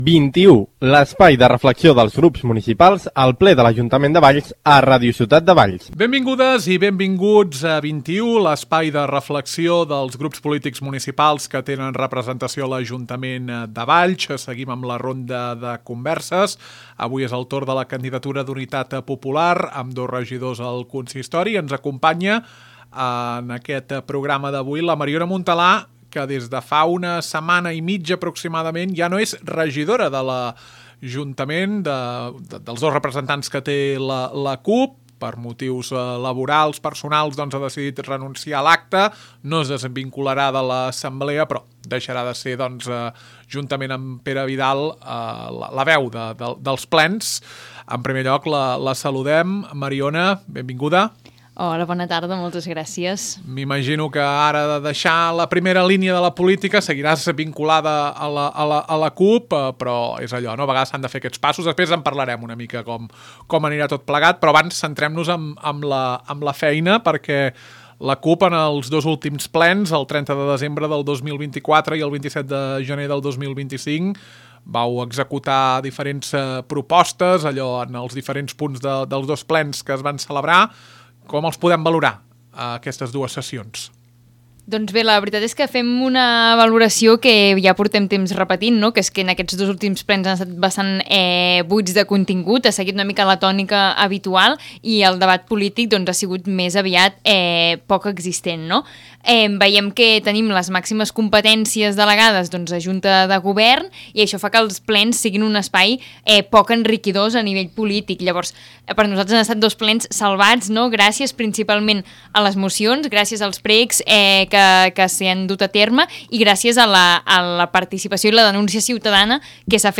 Nova temporada de 21, l’espai de reflexió dels grups municipals que tenen representació al ple de l’Ajuntament de Valls. Avui és el torn de la CUP, grup municipal de l’oposició amb dos regidors. Conversa amb la seva exregidora, Mariona Montalà, qui va renunciar al càrrec en la sessió plenària del passat 27 de gener.